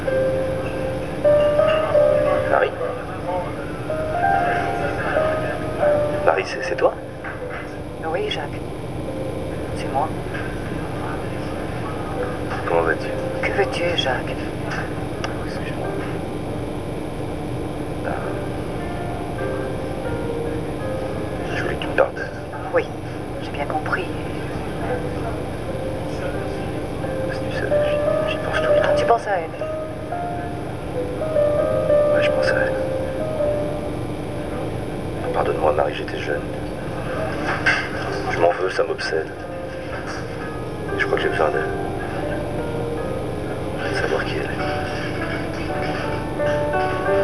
UNE SI LONGUE ABSENCE Pièce sonore d'Elodie Weis Voix